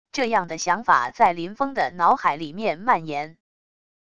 这样的想法在林烽的脑海里面蔓延wav音频生成系统WAV Audio Player